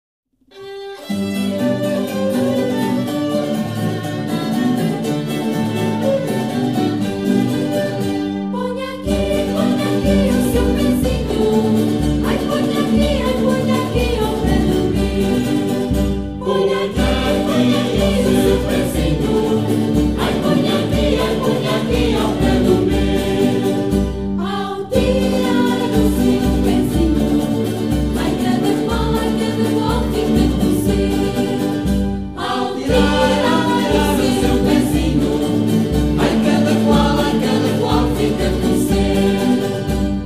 Algumas cantigas do Açores
Gentilmente cedidas pelo excelente "Grupo de Cantares Belaurora" de São Miguel, Açores, Pt.